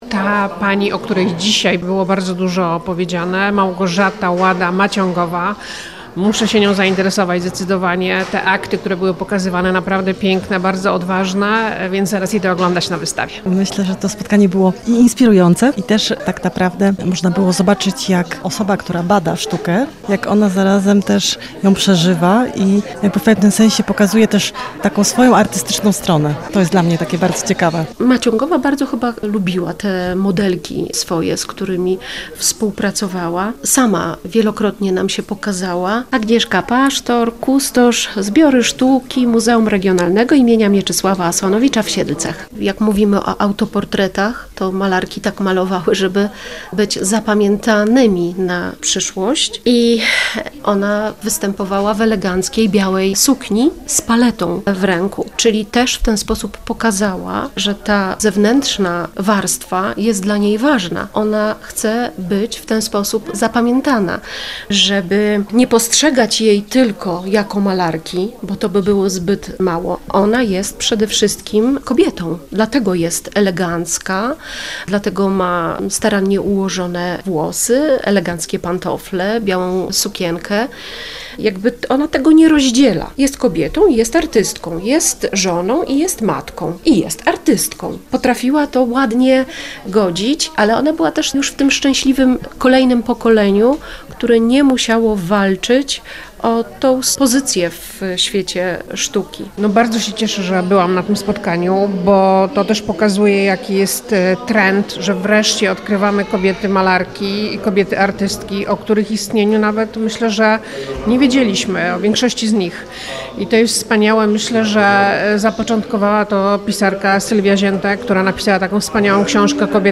Nasza dziennikarka miała przyjemność uczestniczyć w tym wydarzeniu i wysłuchać inspirującej opowieści o tej niezwykłej artystce.